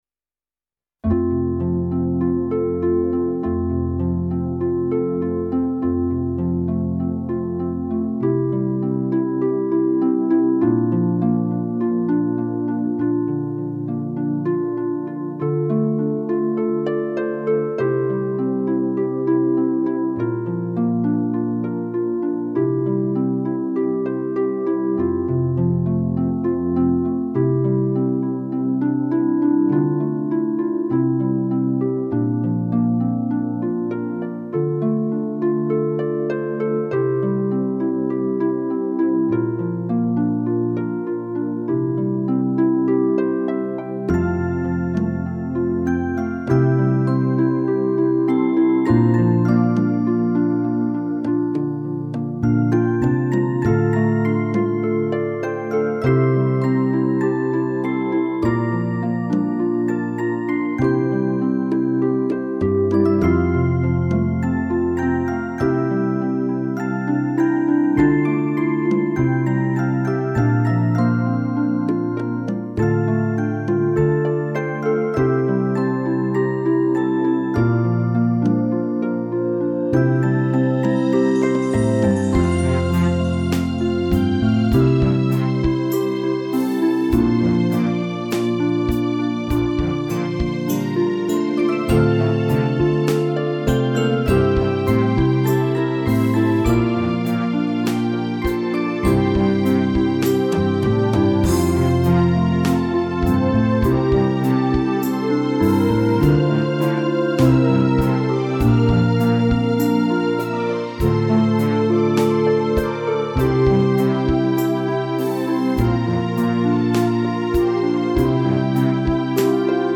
minus Flute